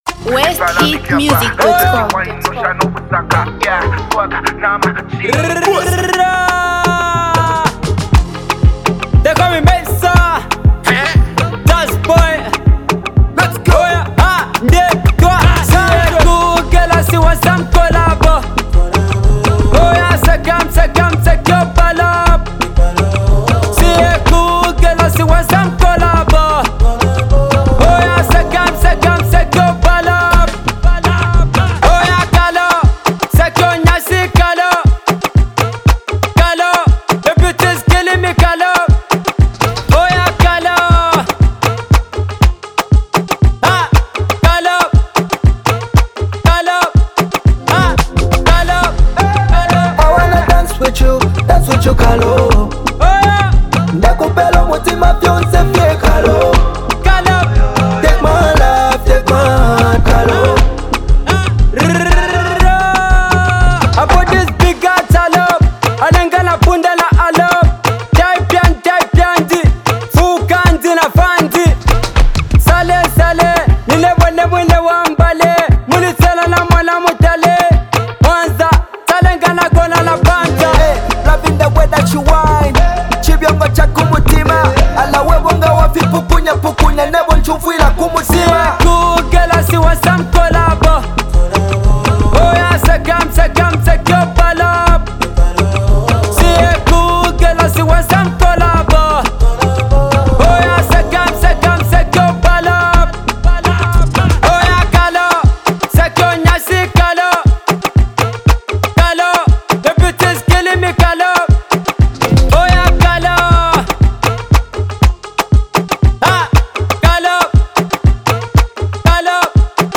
dance track
the talented rapper.